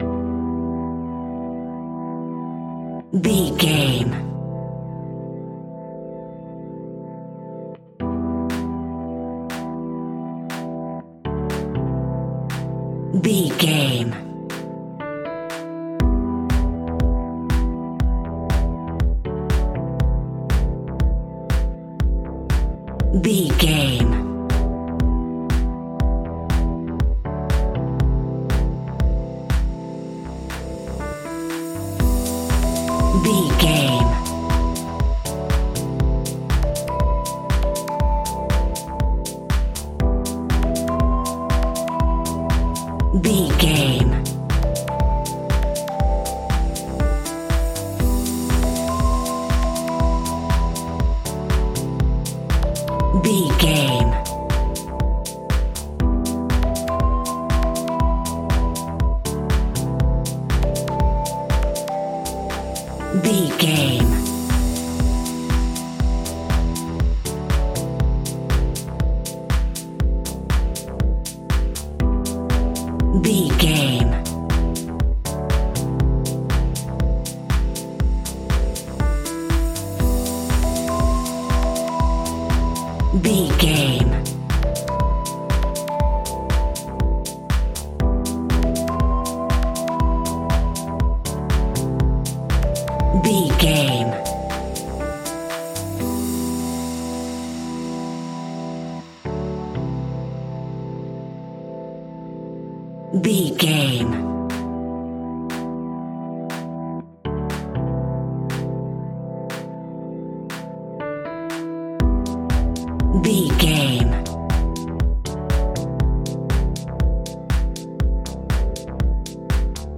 Aeolian/Minor
uplifting
futuristic
driving
energetic
bouncy
synthesiser
drum machine
electro house
progressive house
synth leads
synth bass